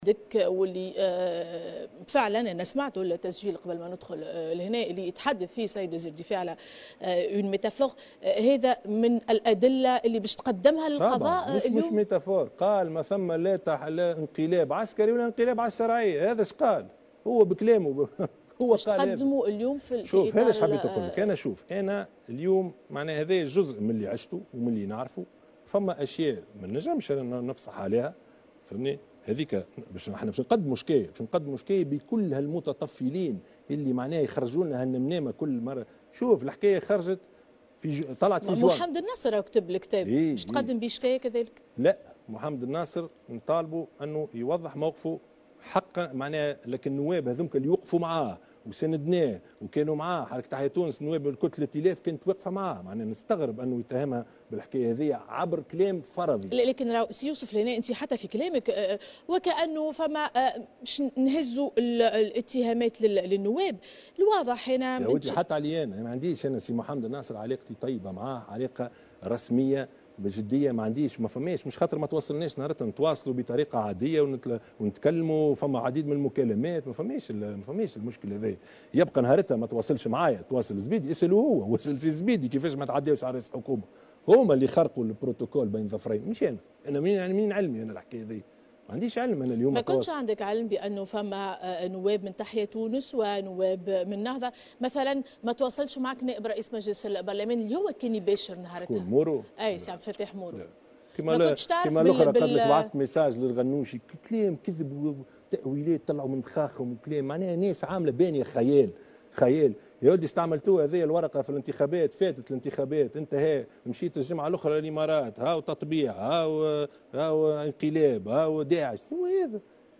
وأضاف في حوار أدلى به أمس لاحدى القنوات التلفزية، أنه يملك تسجيلا صوتيا لوزير الدفاع الأسبق، عبد الكريم الزبيدي يؤكد فيه أنه لم يكن هناك انقلابا عسكريا ولا انقلابا على الشرعية.